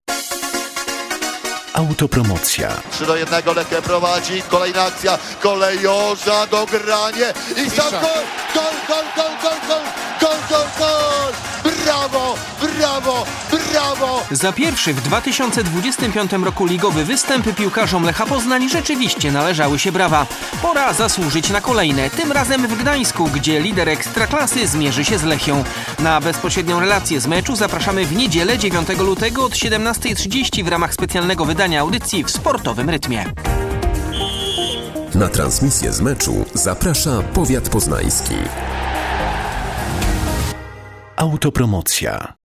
Radiowe zapowiedzi meczów